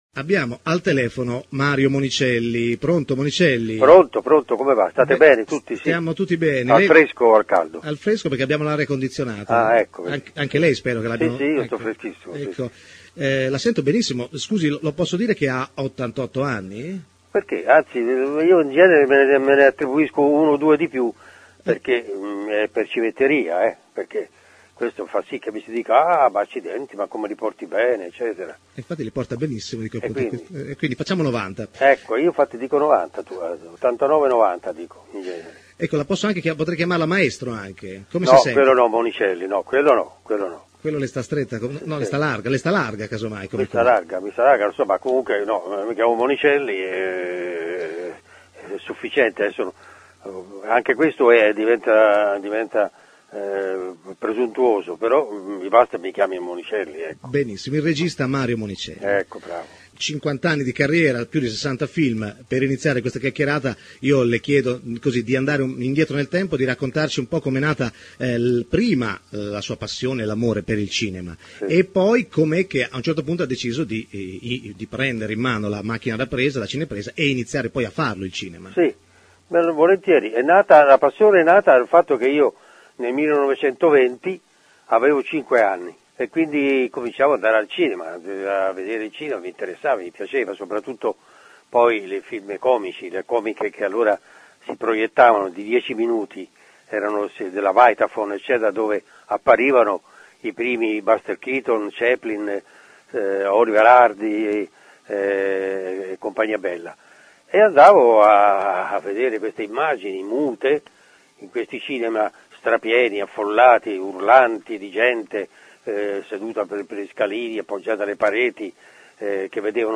Per ricordare il grande Mario Monicelli vi facciamo riascoltare un’intervista realizzata a Humus nel 2002.
intervista-monicelli.mp3